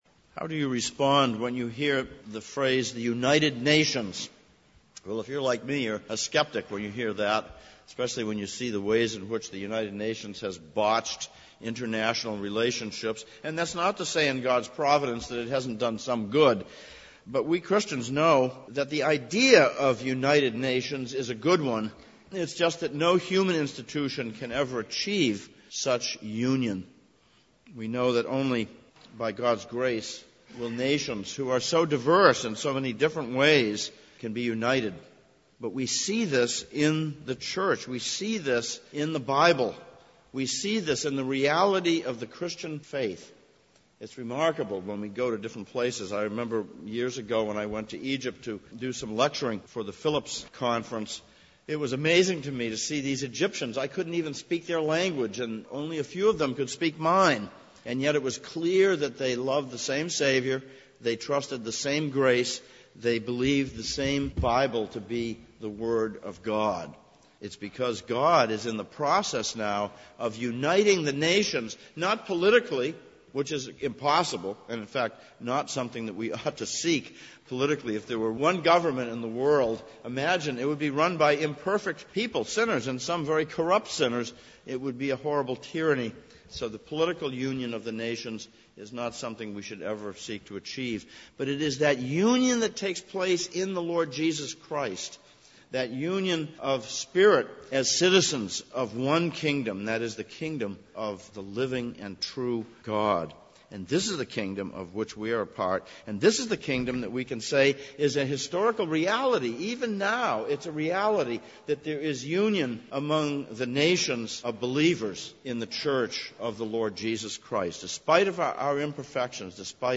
2016 Passage: Psalm 117:1-2, Romans 15:1-13 Service Type: Sunday Morning « 20.